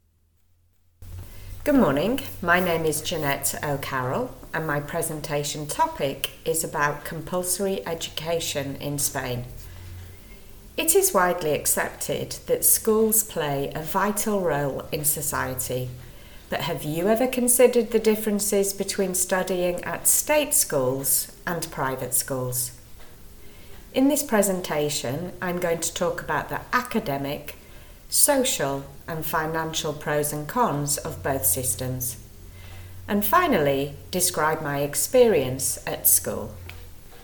• Exam-ready recorded monologue (MP3)
b2-monologue-school-systems-private-state-sneak-peek.mp3